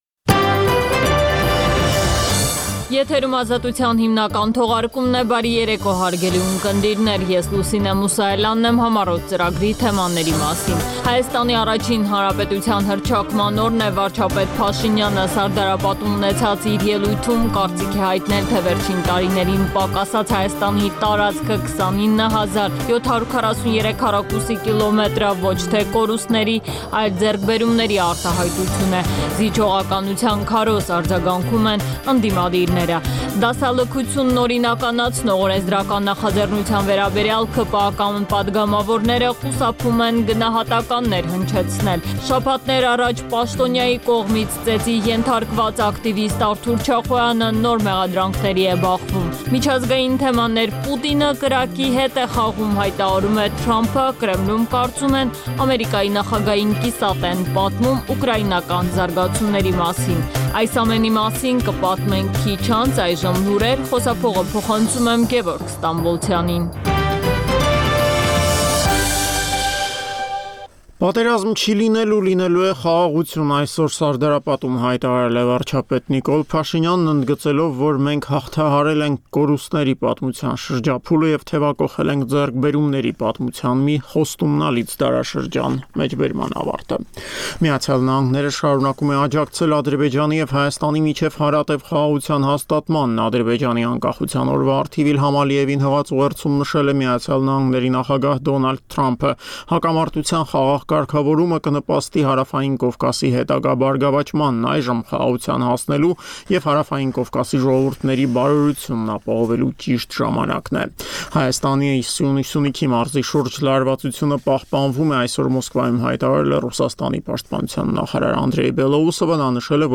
Listen Live - Ուղիղ հեռարձակում - Ազատություն ռ/կ